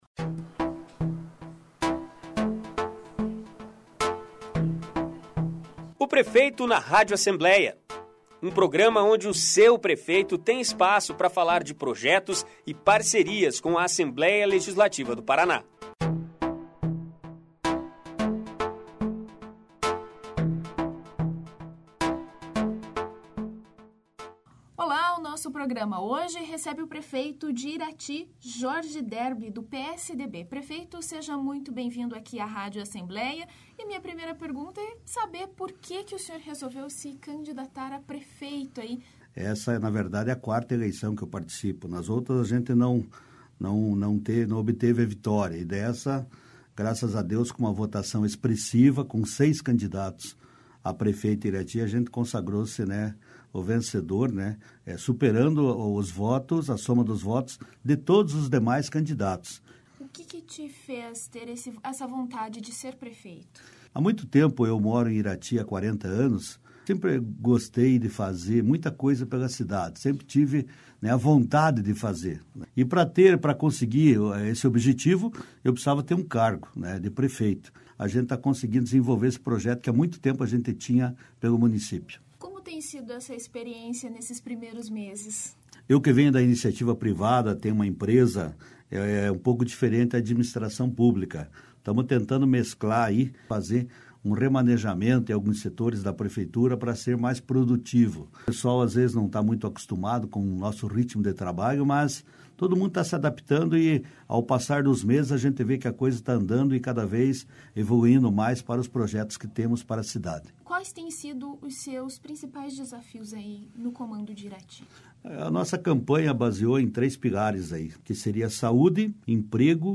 Ouça entrevista com o prefeito de Irati, Jorge Derbi (PSDB) no programa  "Prefeito na Rádio Alep" desta semana.